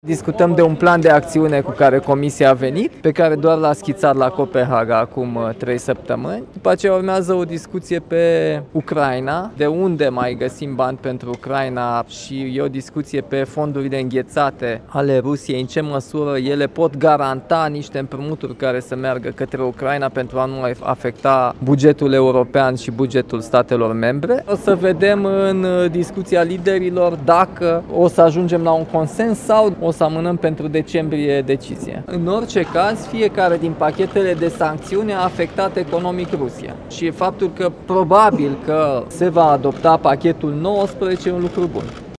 Președintele Nicușor Dan, la reuniunea Consiliului European: Fiecare din pachetele de sancțiuni adoptate a afectat economic Rusia | AUDIO